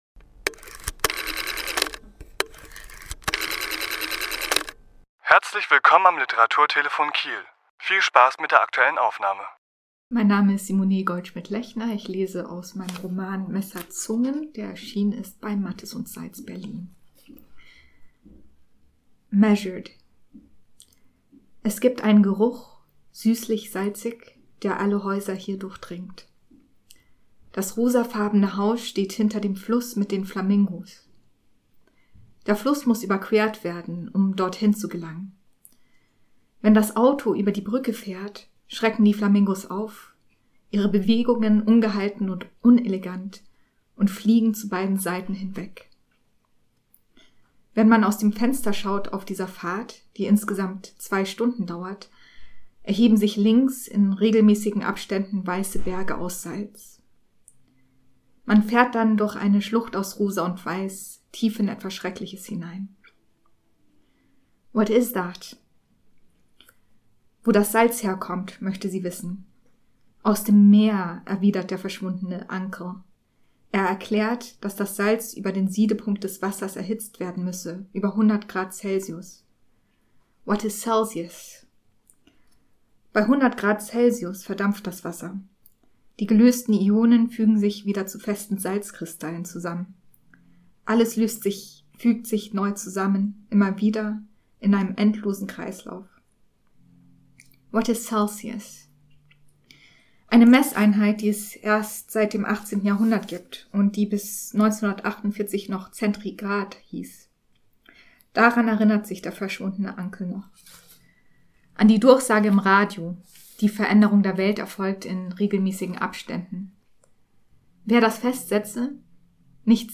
Autor*innen lesen aus ihren Werken
Die Aufnahme entstand im Rahmen der LeseLounge am 9.11.2022 im Literaturhaus Schleswig-Holstein.